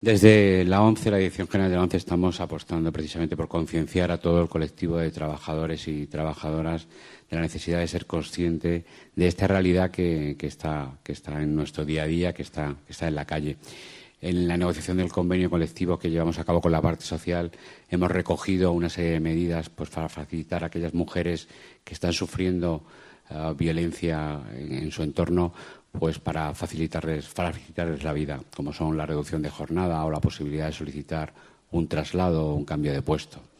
Por un lado, el salón de actos de la Dirección General de la ONCE en Madrid acogió el acto institucional ‘El amor.. ¿es ciego?’Abre Web externa en ventana nueva, con el que la Organización se sumó a este Día.